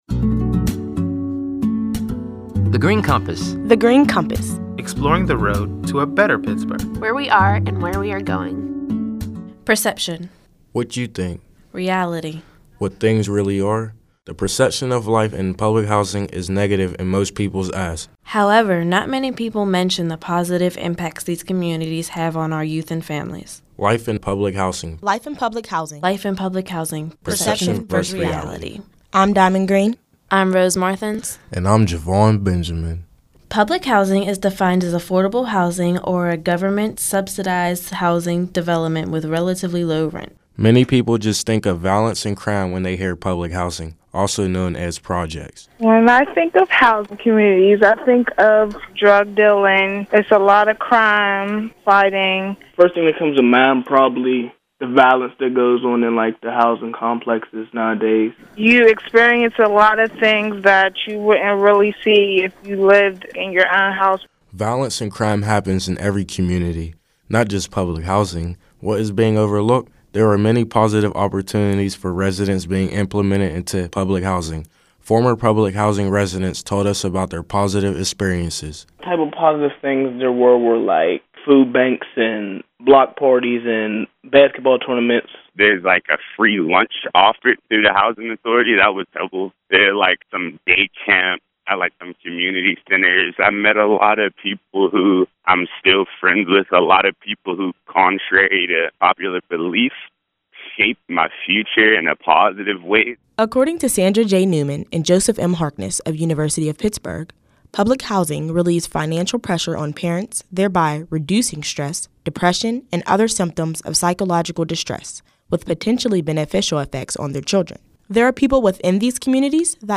In July 2014, thirty-two recent high-school graduates created these radio features while serving as Summer Interns at The Heinz Endowments.